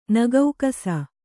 ♪ nagaukasa